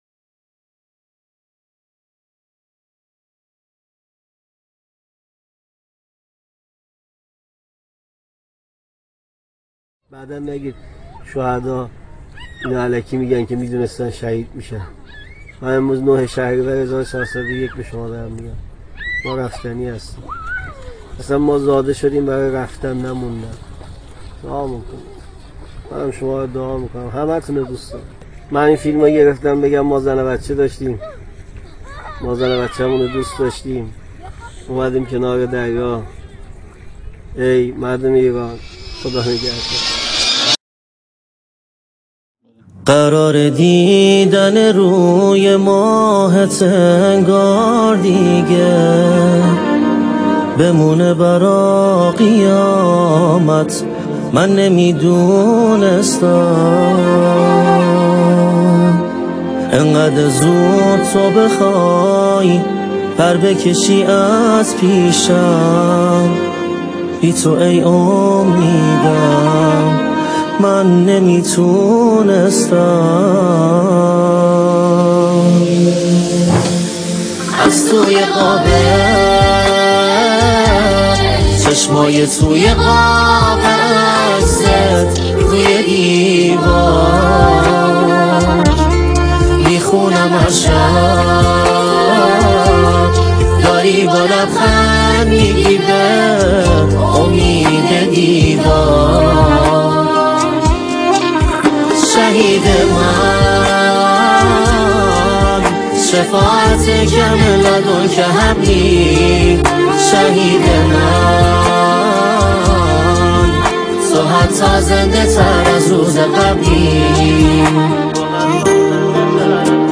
ژانر: سرود ، سرود انقلابی